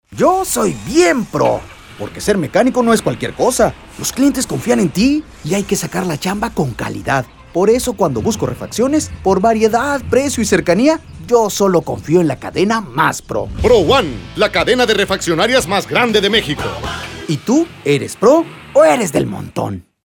Spot de Radio